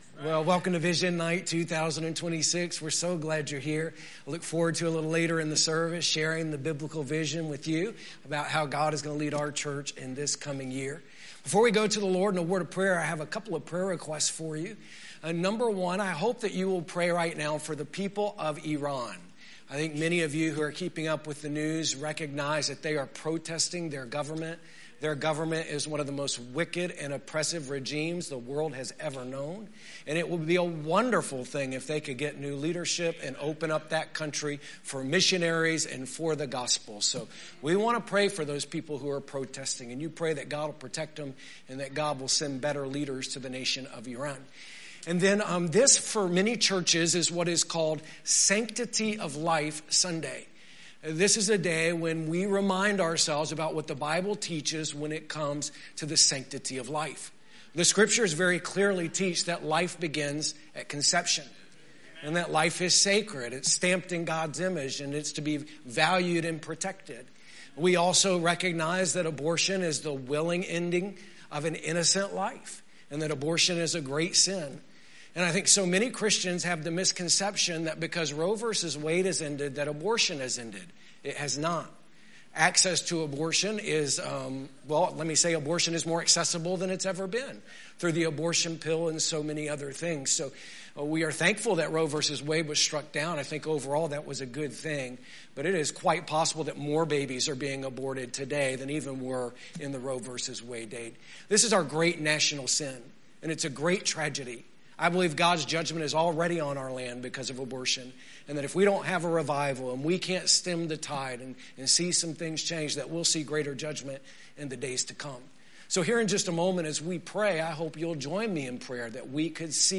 Sunday Evening
Sermons